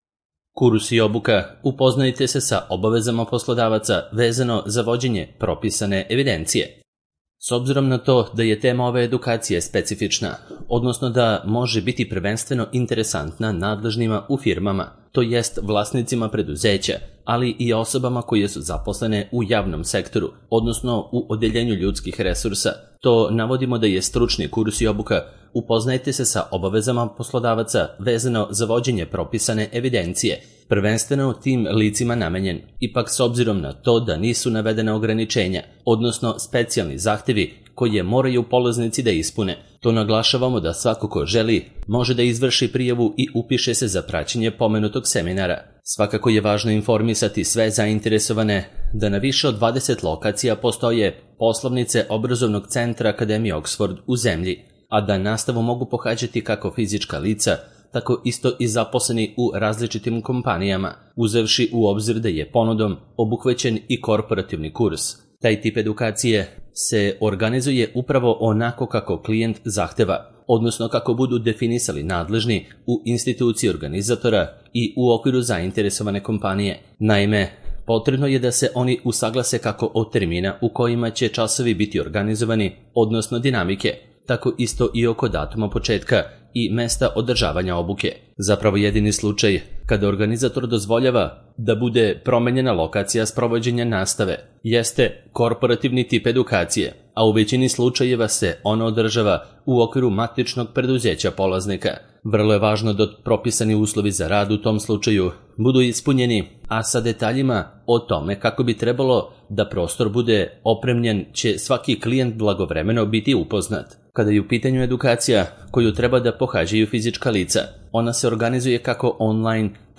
Audio verzija teksta